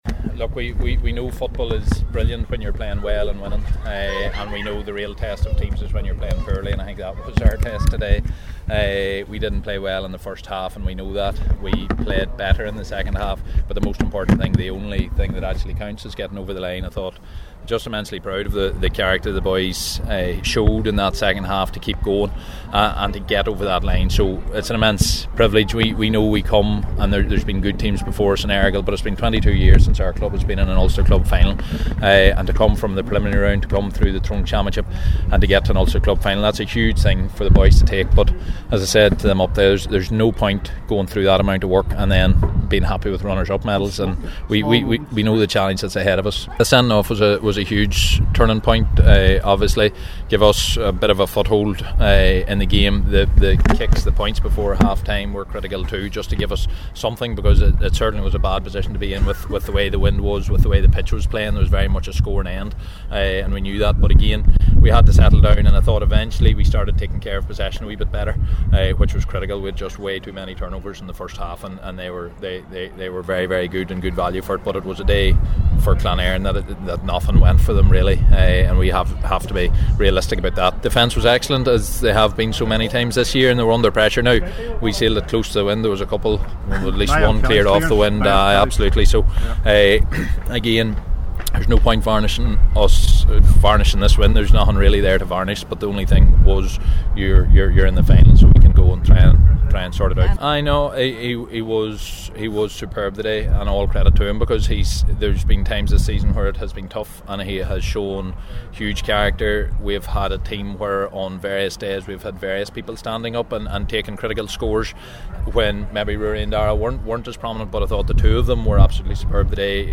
After the game, Enda McGinley spoke to the media and said “Kilcoo showed last night the level we have to get to”…